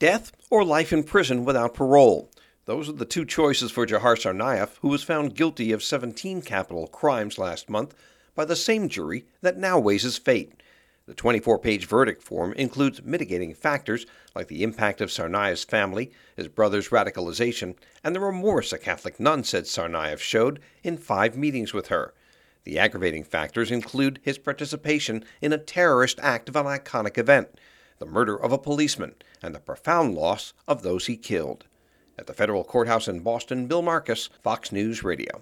FROM OUTSIDE THE FEDERAL COURTHOUSE IN BOSTON.